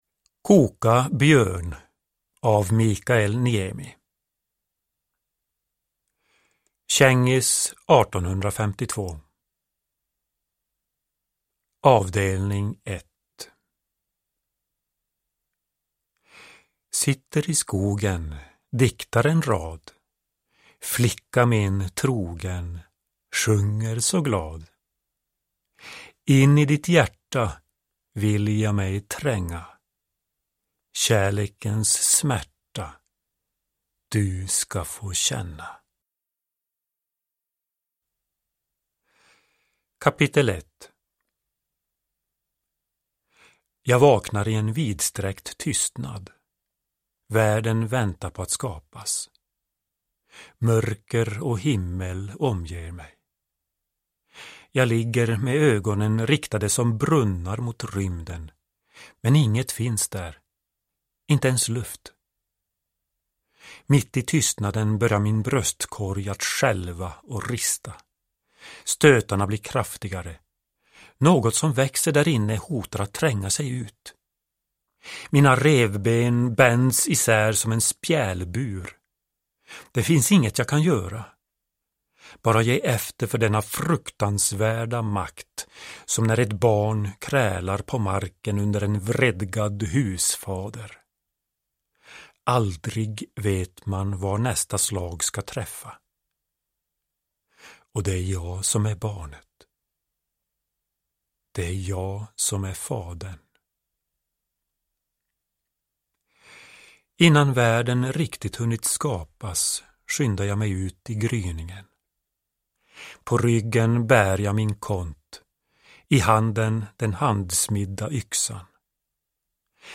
Koka björn / Ljudbok